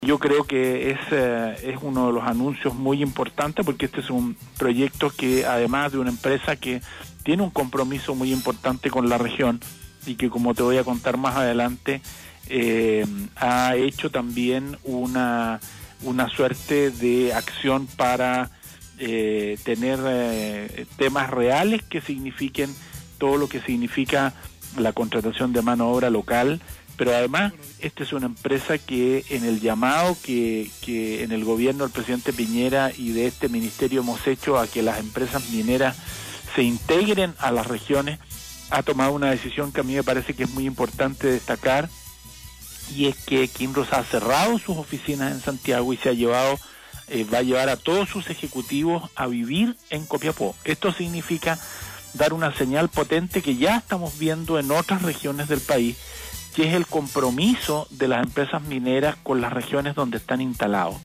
En un contacto exclusivo con Nostálgica, Baldo Prokurica, Ministro de Minería, se refirió a la importancia de tres proyectos que son una realidad para Atacama, ya que considera que no son planes que se estén recién iniciando o estudiándose, sino que ya se han anunciado fecha para su materialización.